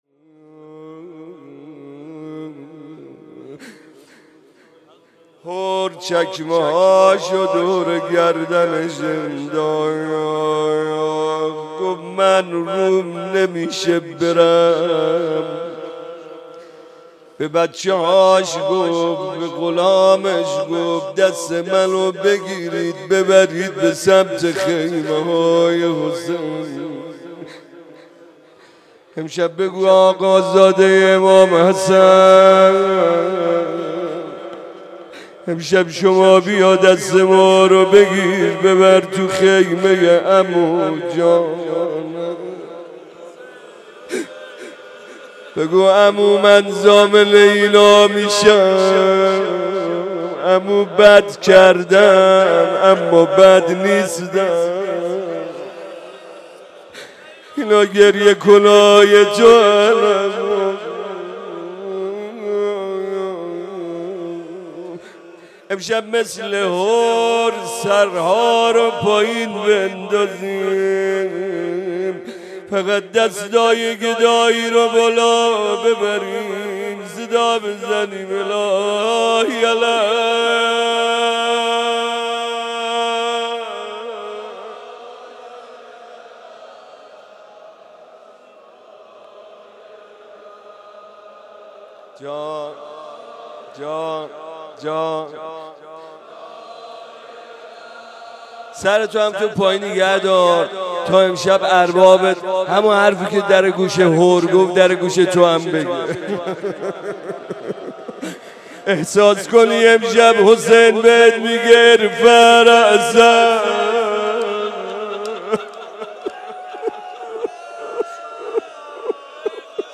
روضه حضرت قاسم بن الحسن علیه السلام